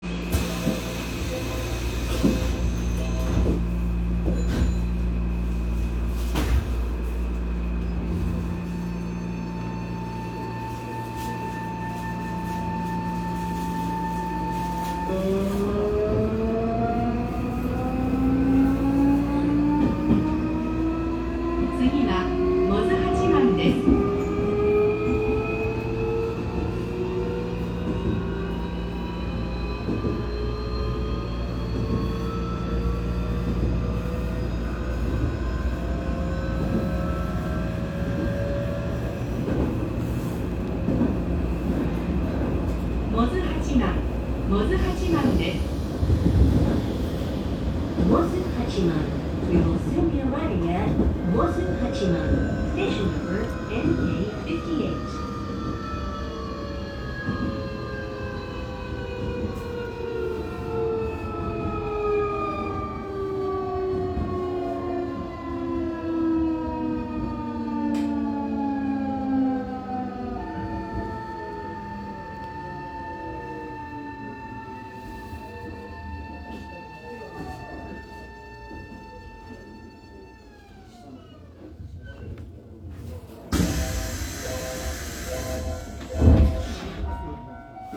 ・8300系走行音
【高野線】三国ヶ丘→百舌鳥八幡
SiCか？と思うような変わったモーター音ですが、全閉内扇形誘導電動機を採用している事からこのような音になっているだけで、特にSiCやPMSMではない日立IGBTとの事です。非常に聞きごたえのある大きな音なので、収録し甲斐はある車両だと言えそうです。
mikunimozu.m4a